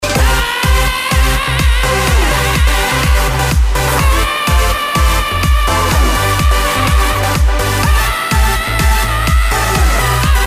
screamingcowboy Download
screamingcowboy.mp3